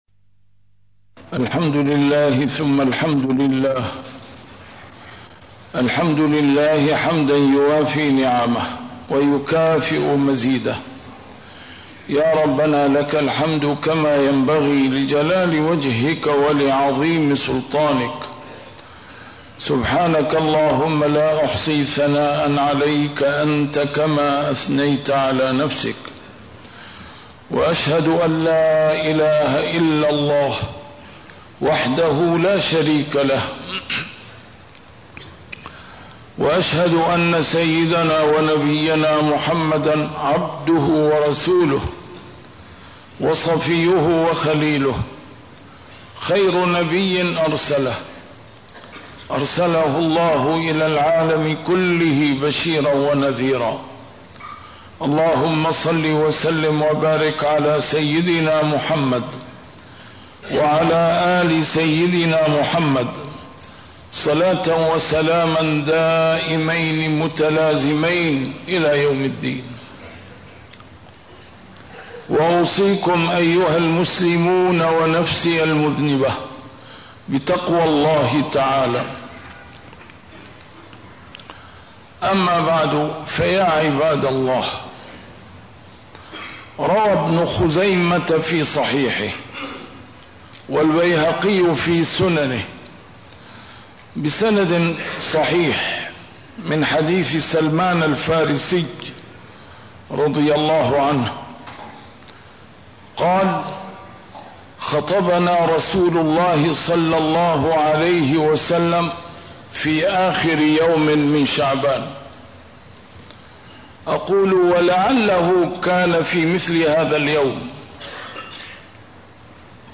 A MARTYR SCHOLAR: IMAM MUHAMMAD SAEED RAMADAN AL-BOUTI - الخطب - رمضان شهر النصر .. ولكن؟!